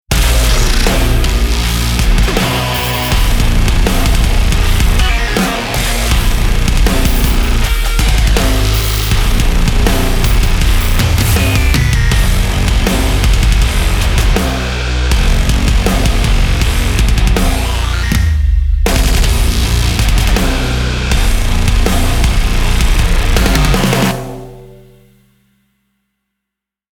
• ダブル・キック・ドラムを真のステレオ・ポジショニングで収録
獰猛で、巨大なドラムをより強く叩く
ドラムがギターの壁を切り裂く。